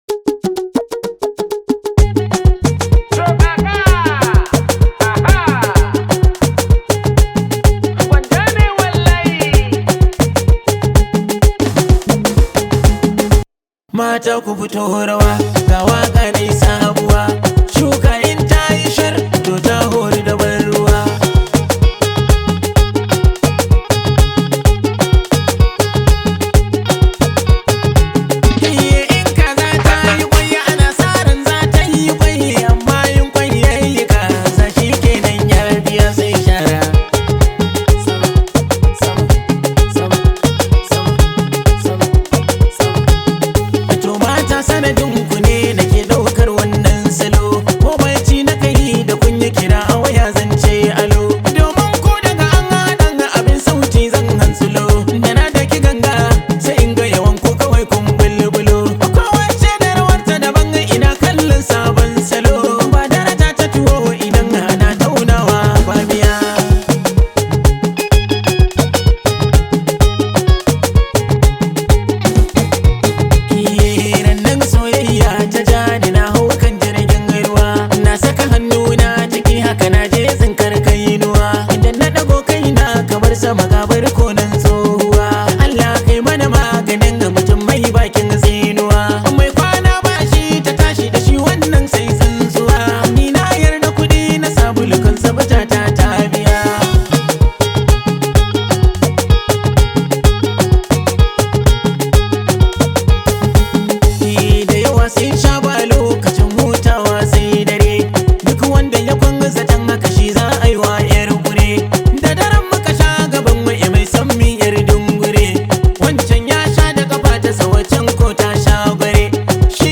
much appreciated hausa song known as
This high vibe hausa song